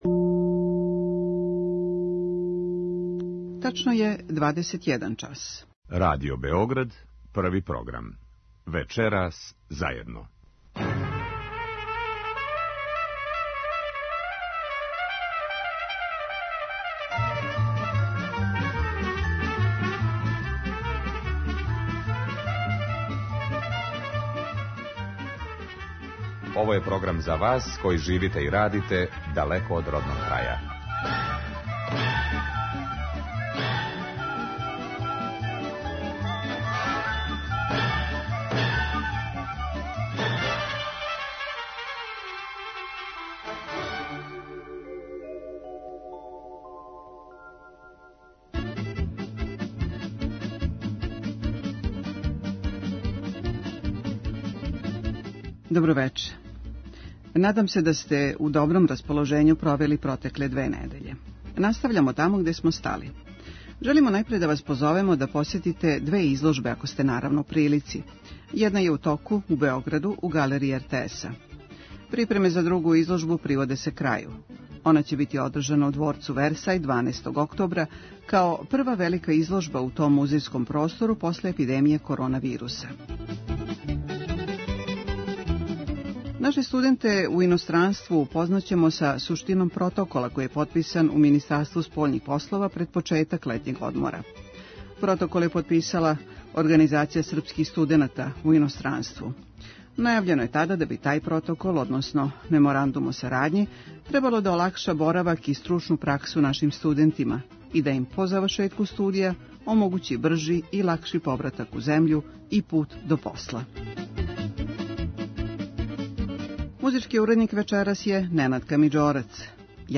Емисија магазинског типа која се емитује сваког петка од 21 час.
Концепцију не мењамо: вести из дијаспоре и региона, занимљиви гости и, наравно, музика „из родног краја" за добро расположење.